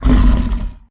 Angry Chimera Growls
tb_growl_2.ogg